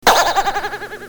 SFX弹簧2音效下载
SFX音效